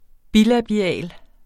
Udtale [ ˈbilabiˌæˀl ]